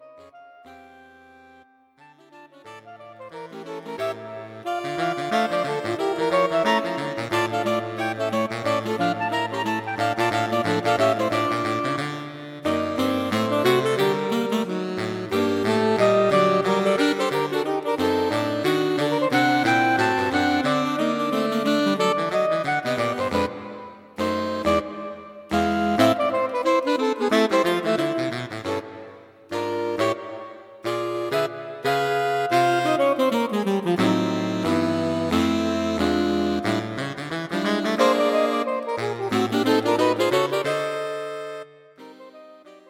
Saxophone Quartet for Concert performance